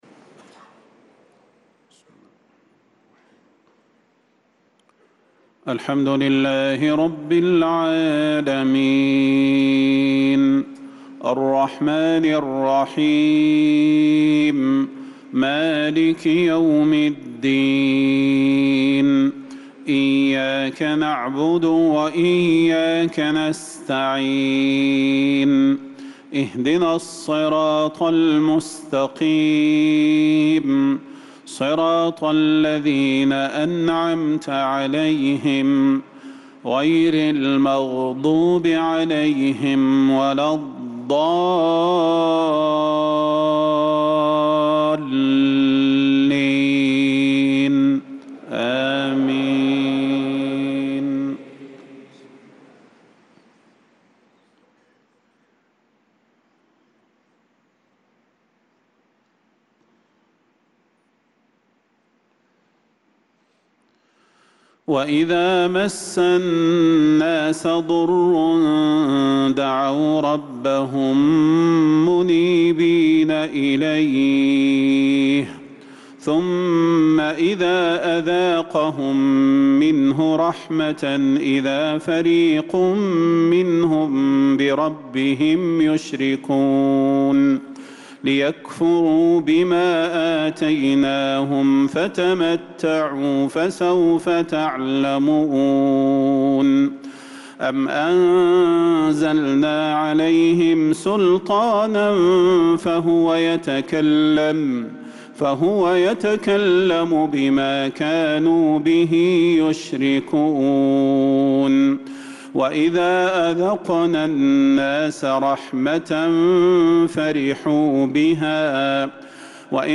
صلاة المغرب للقارئ صلاح البدير 9 ربيع الأول 1446 هـ
تِلَاوَات الْحَرَمَيْن .